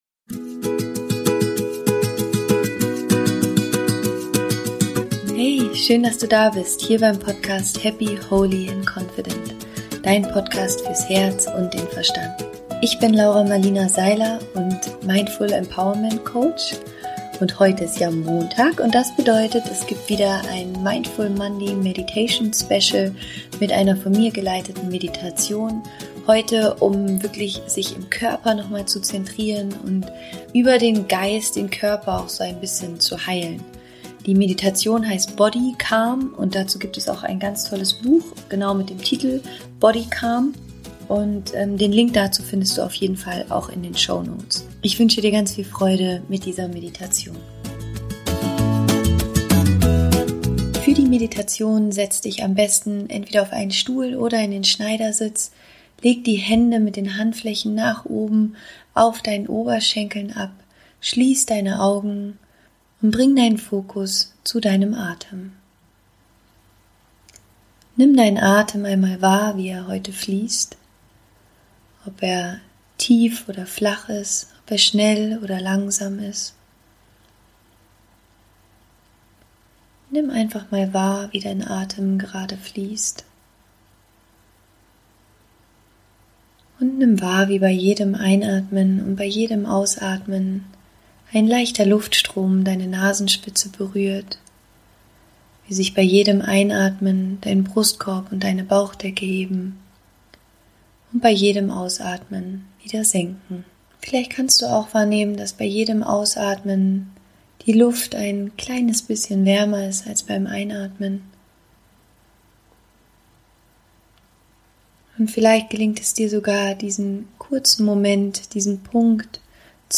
Body Calm Meditation zur Entspannung